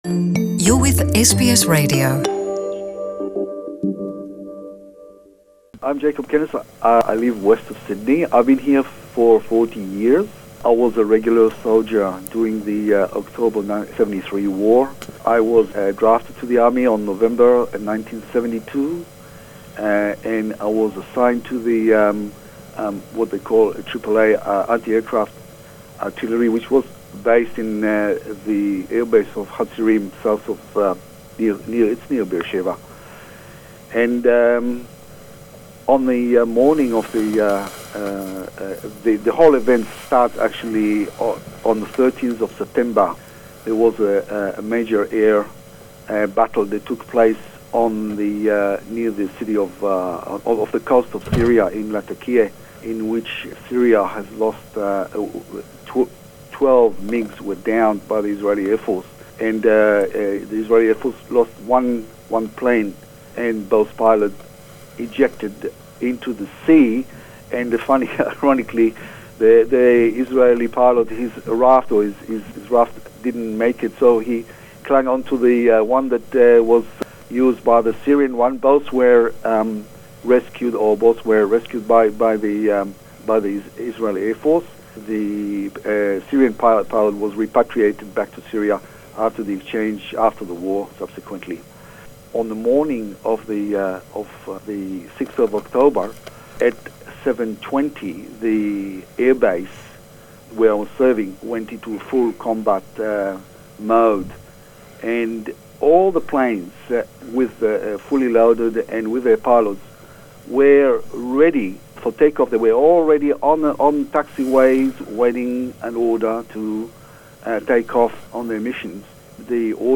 Interview in English